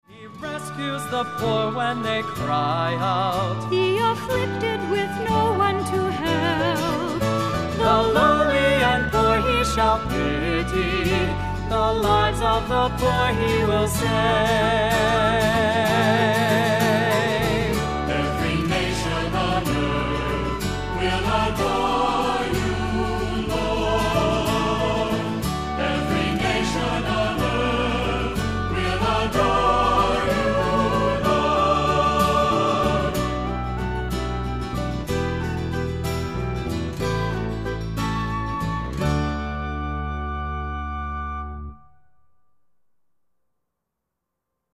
Accompaniment:      Keyboard, C Instrument I;C Instrument II
Music Category:      Christian
Assembly and C instrument parts are optional.